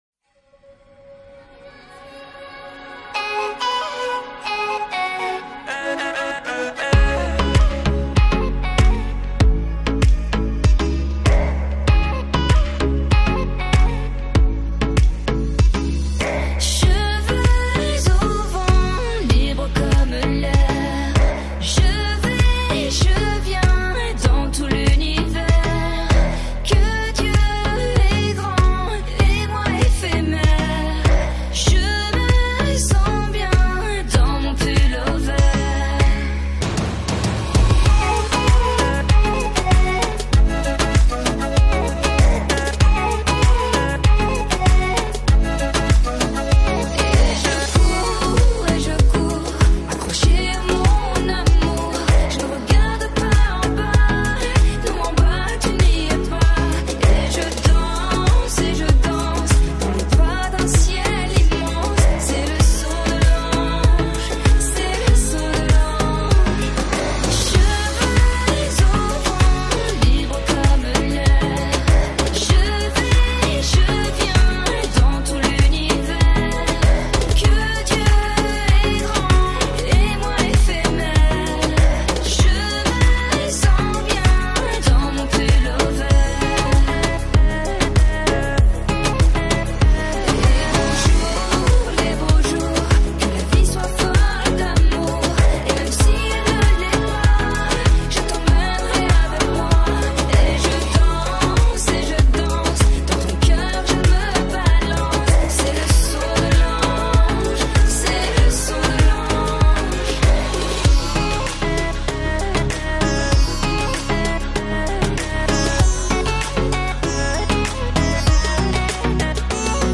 яркая и мелодичная поп-песня французской певицы